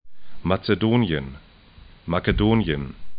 matse'do:nĭən